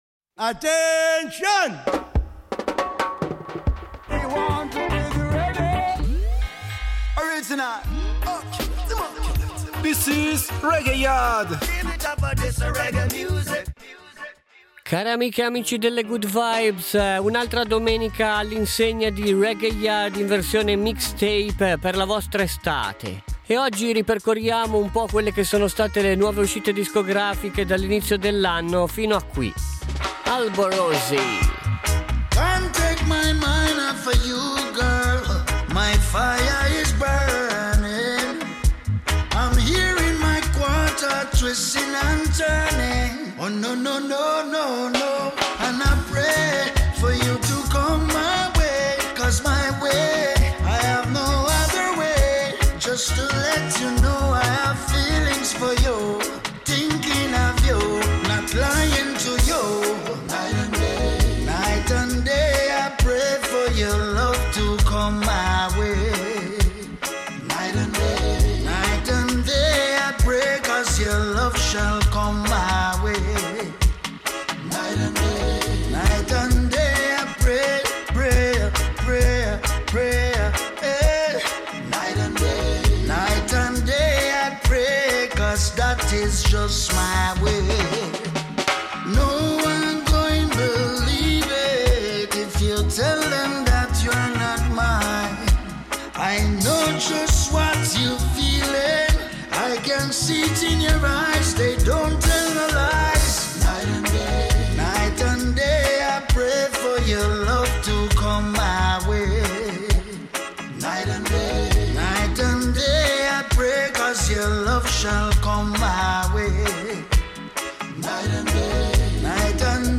REGGAE / WORLD